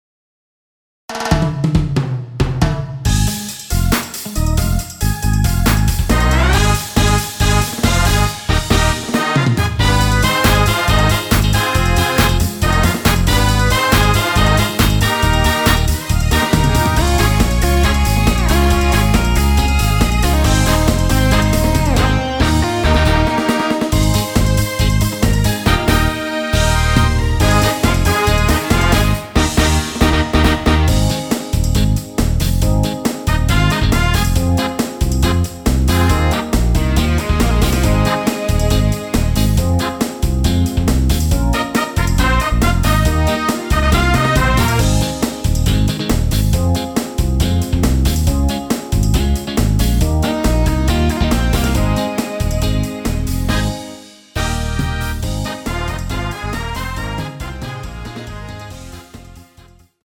원키에서(-2)내린 MR입니다.
Ab
앞부분30초, 뒷부분30초씩 편집해서 올려 드리고 있습니다.